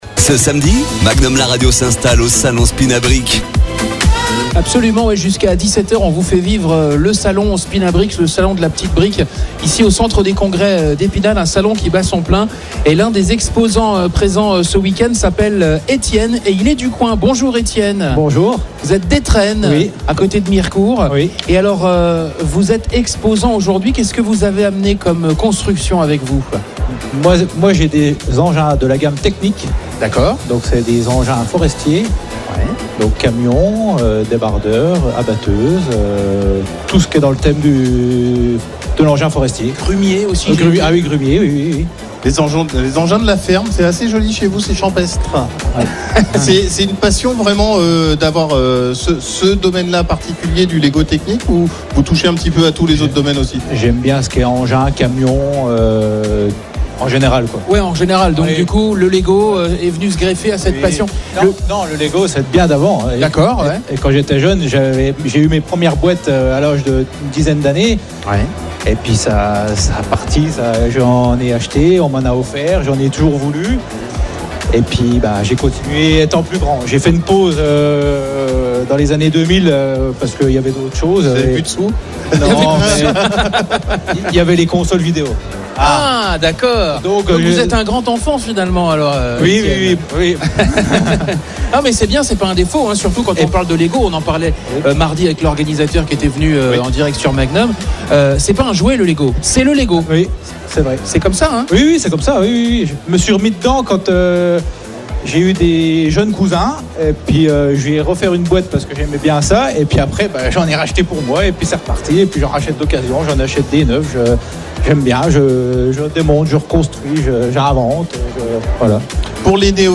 Emission spéciale en direct du salon SPINABRICKS au centre des congrès d'Épinal
Interview